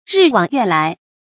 日往月來 注音： ㄖㄧˋ ㄨㄤˇ ㄩㄝˋ ㄌㄞˊ 讀音讀法： 意思解釋： 形容歲月流逝。